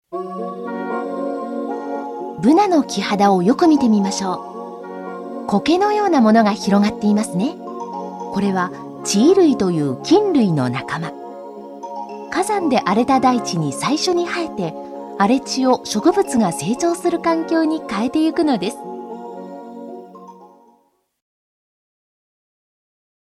日本語 女性｜ナレーター紹介